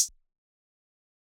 Closed Hats